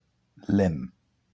Captions English Lympne Pronunciation